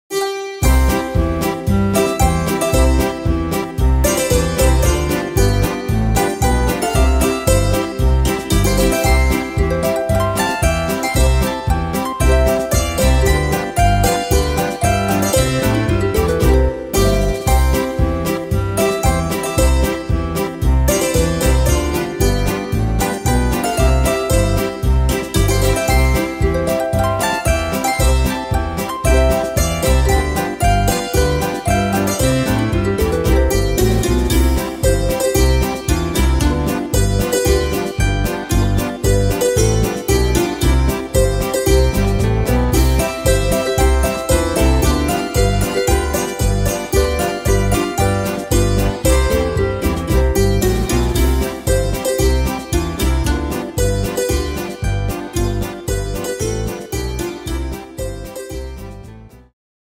Tempo: 114 / Tonart: C-Dur